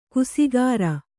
♪ kusigāra